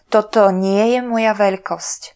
Slovak voice announciation